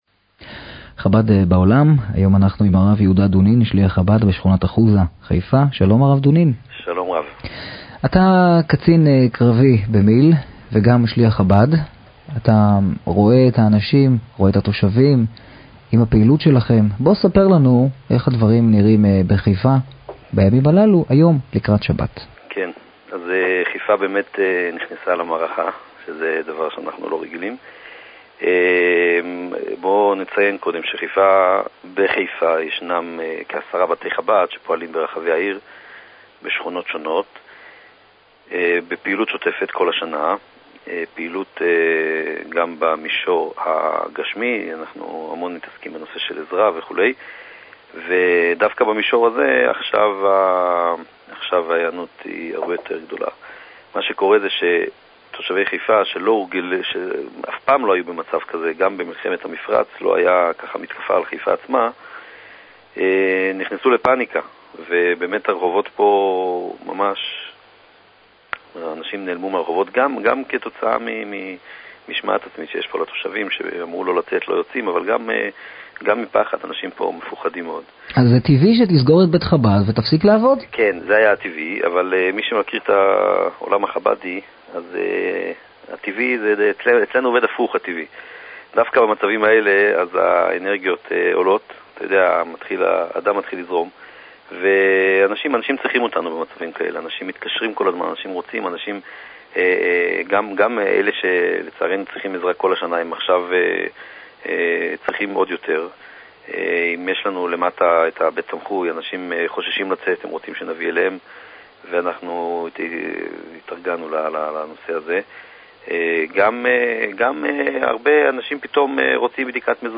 טוב שיש שלוחים שיודעים לדבר בצורה מכובדת ומקצועית